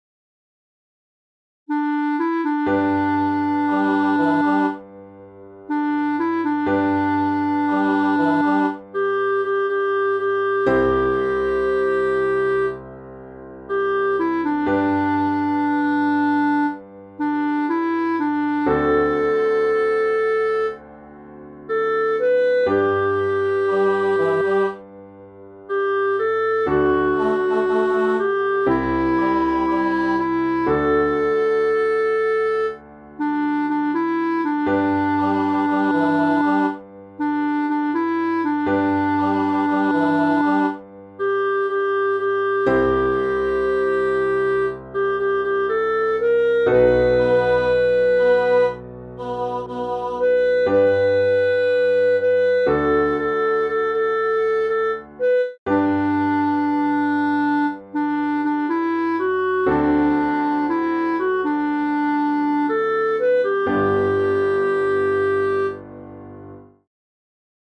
Haere Mai (Cançó tradicional de Nova Zelanda)
Interpretació musical de la versió instrumental de la cançó tradicional de Nova Zelanda